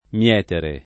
mL$tere] v.; mieto [mL$to] — dittongo -ie- (da -e- breve tonica del lat. metere [m$tere]) conservato contro la regola del dittongo mobile in tutta la coniug. del verbo, comprese le forme rizotoniche (es. mietiamo, mieterò, mietuto), e anche nelle voci derivate (mietitore, mietitrice, mietitura) — possibile una volta tanto, per preziosità poetica, una forma senza dittongo: Metuto fu dalla più grande falce [met2to f2 ddalla pLu ggr#nde f#l©e] (D’Annunzio)